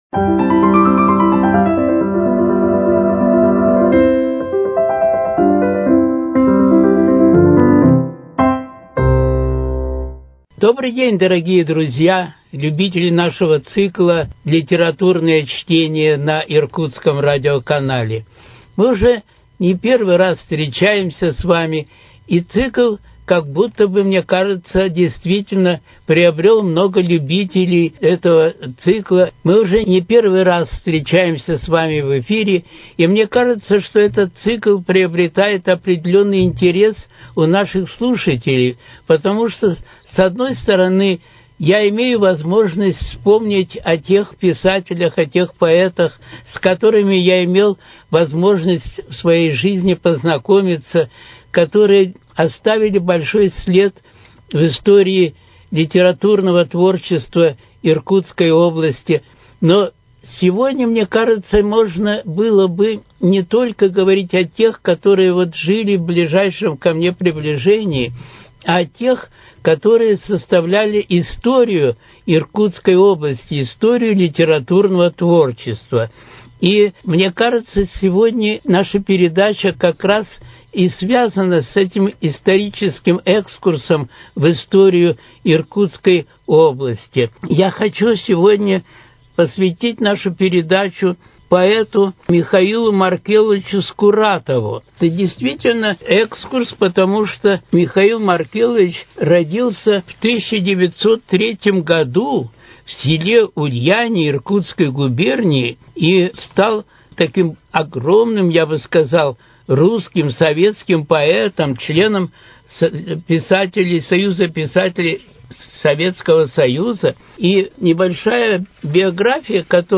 читает в эфире произведения классиков и современников. В этом он знакомит слушателей с творчеством поэта Михаила Скуратова.